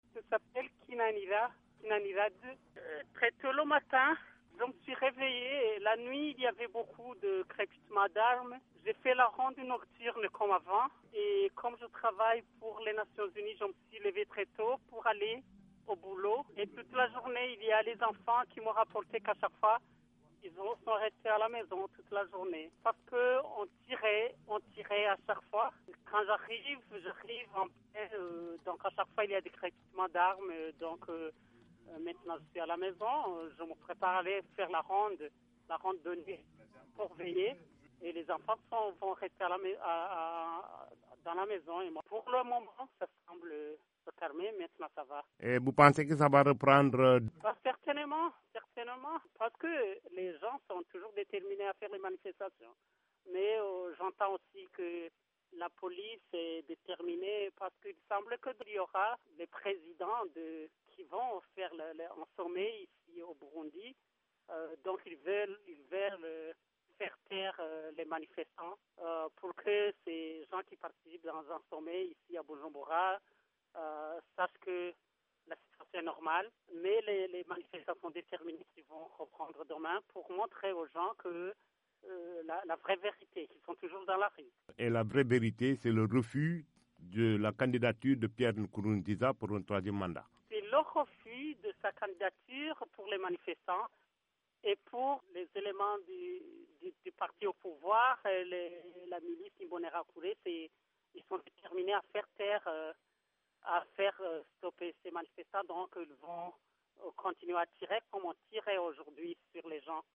Un habitant de Musaga au téléphone